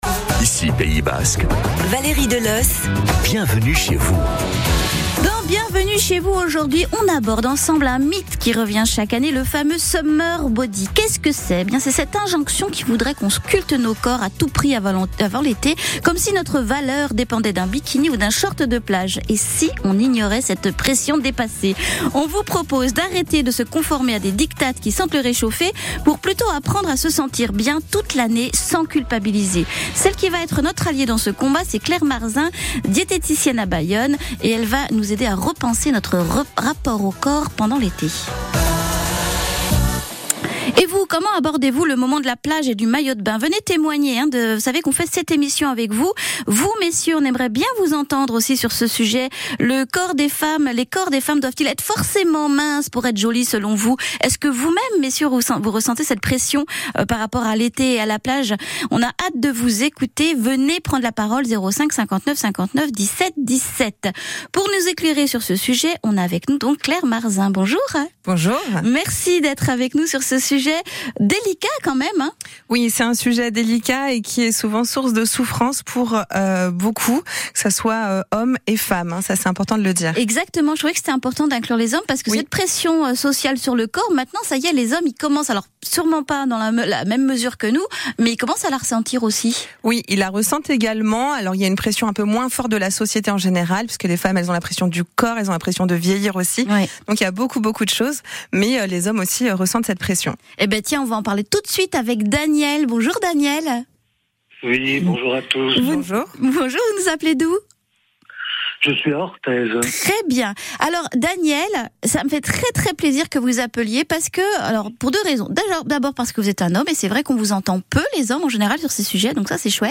Passage radio fin mars mais d’actualité maintenant: Le Summer Body